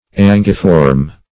\An"gui*form\